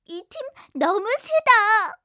ui_yell_0_30.wav